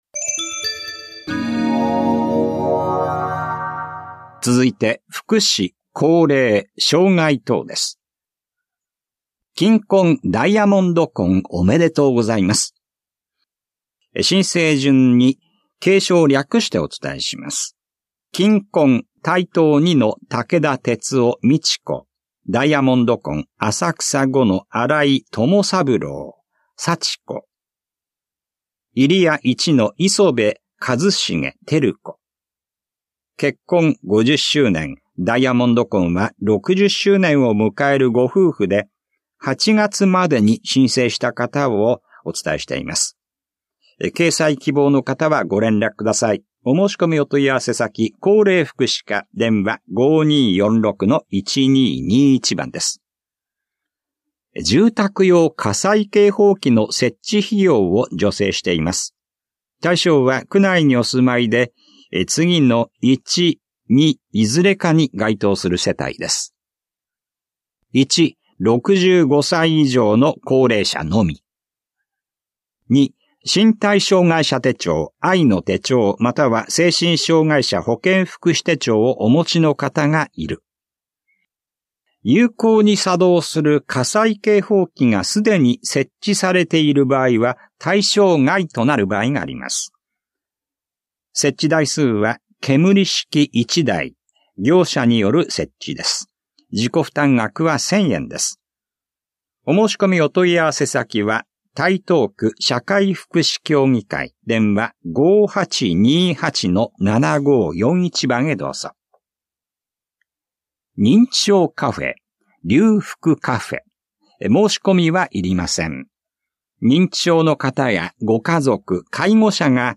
広報「たいとう」令和6年10月20日号の音声読み上げデータです。